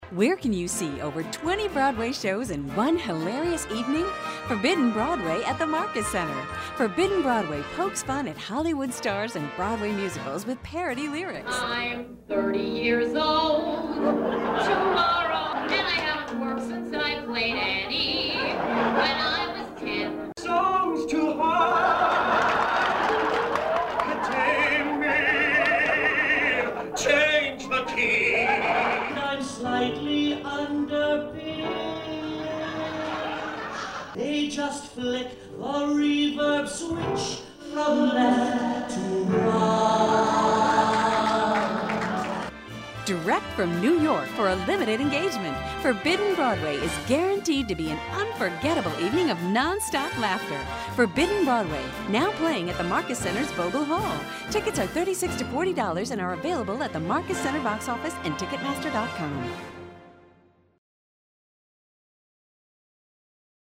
Forbidden Broadway Radio Commercial